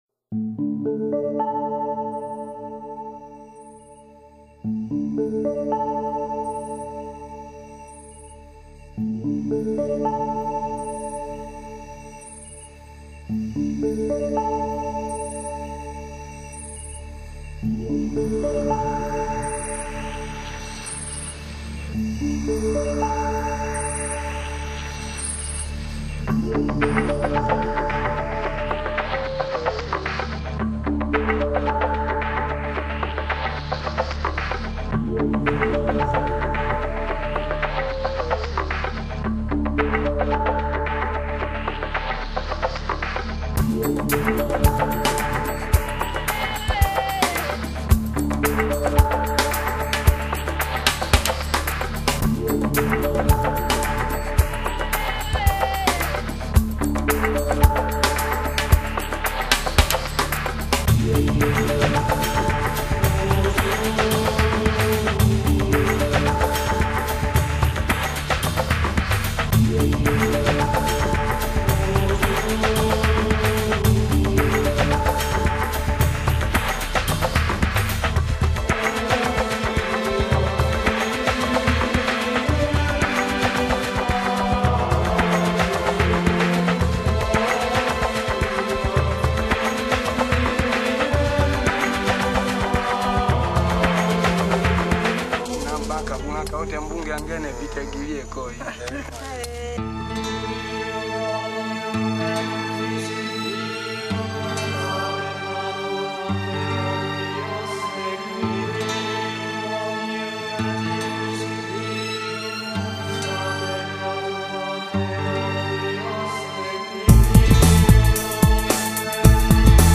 其音樂融合了電子舞曲、環境音樂、工業噪聲、部族音樂、新古典音樂等各種風格